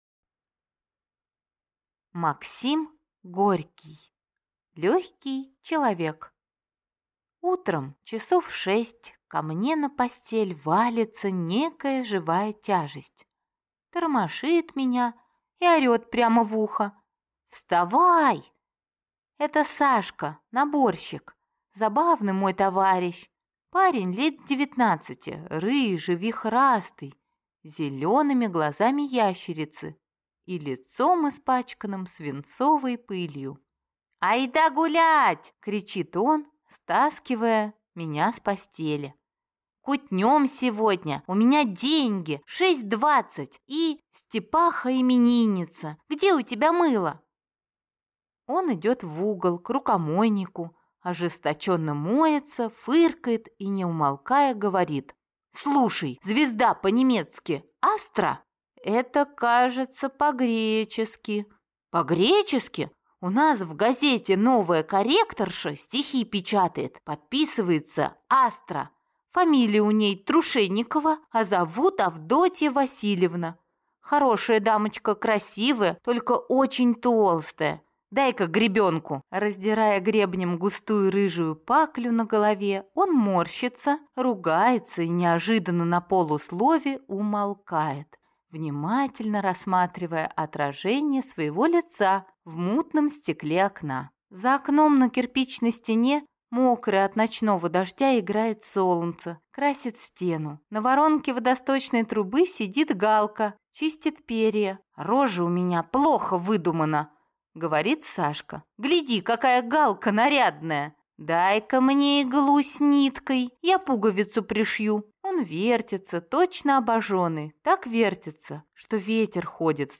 Аудиокнига Легкий человек | Библиотека аудиокниг